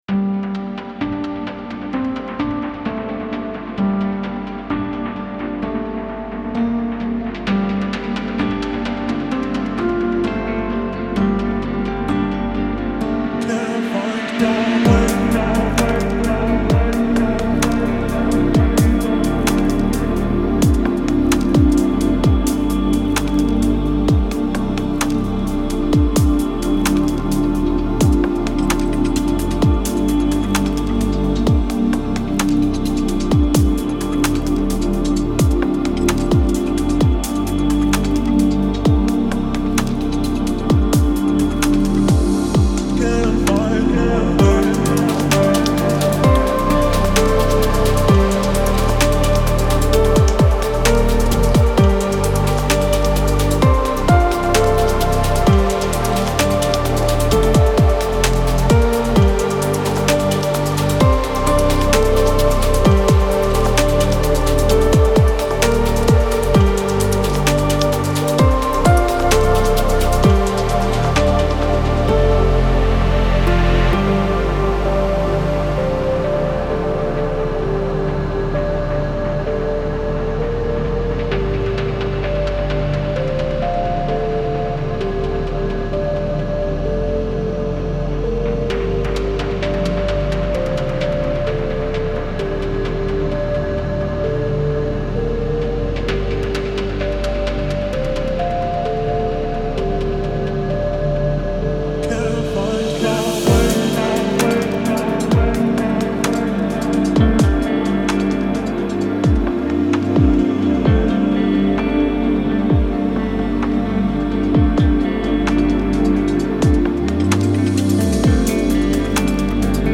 ریتمیک آرام
چیل ترپ